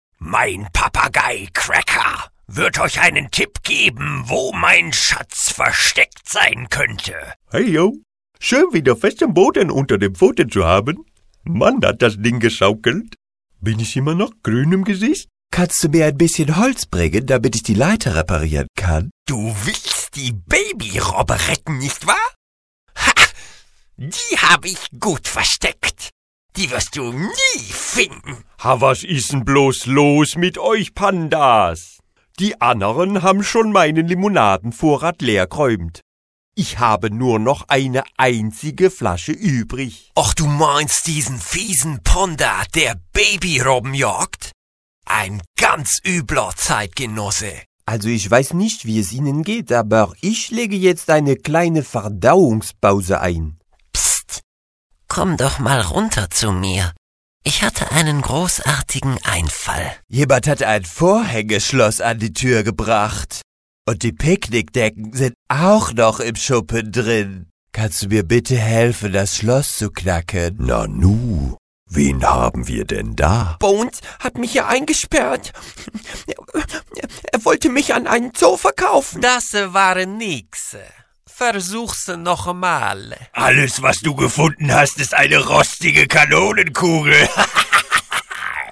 Santiago Ziesmer (Berlin) spricht deutsch Santiago Ziesmer wurde 1953 in Spanien geboren und zog ein Jahr später mit der Familie nach Deutschland.